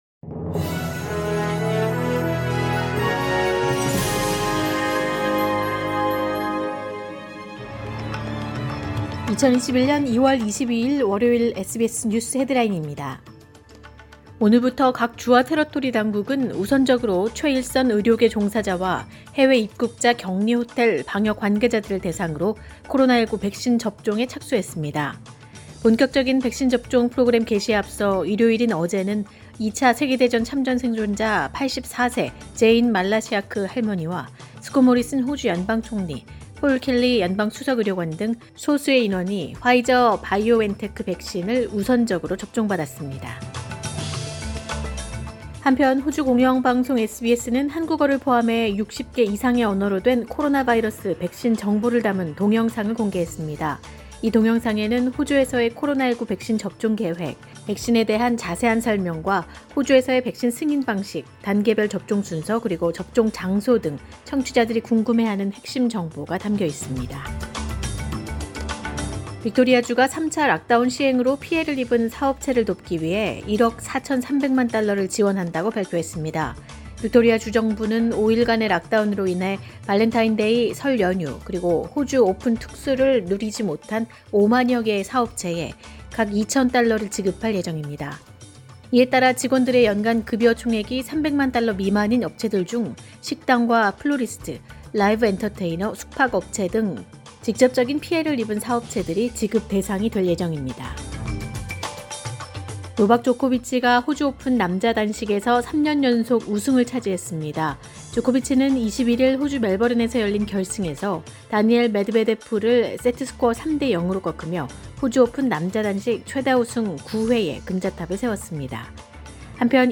2021년 2월 22일 월요일 오전의 SBS 뉴스 헤드라인입니다.